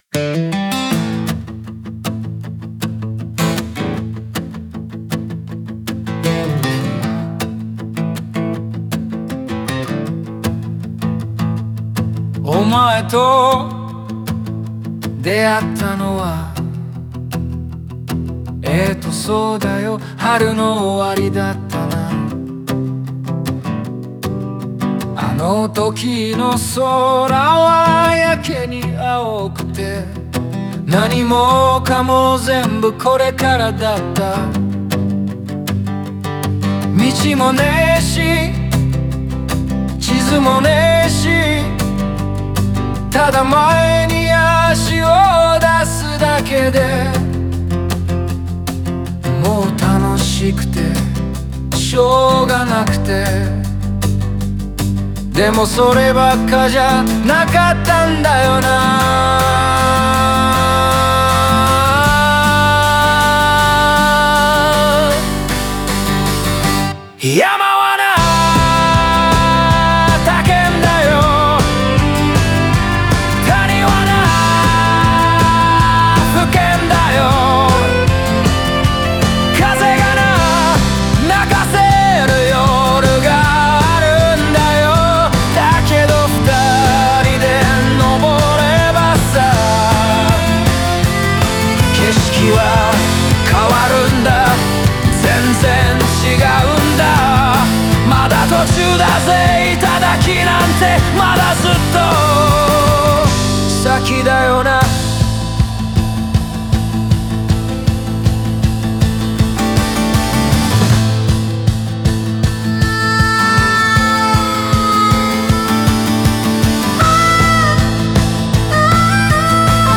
語りかけるような口語表現と字あまりのリズムで、感情の揺れや思いを押し切るように伝え、歌詞全体にリアルな息遣いが生まれる。